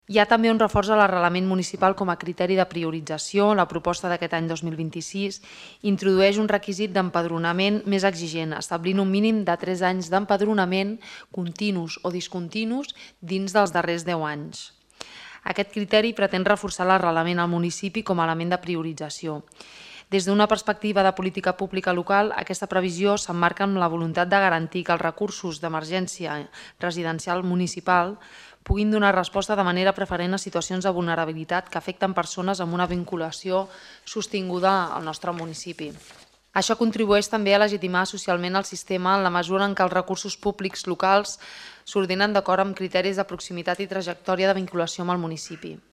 Cristina Dalmau, regidora Benestar Social